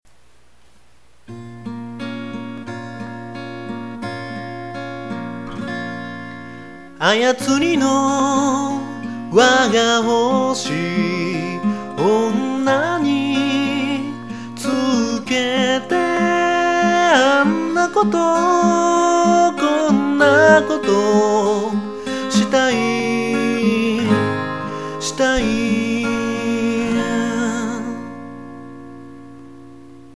録音環境が整ってないので音割れしまくるのは仕様！！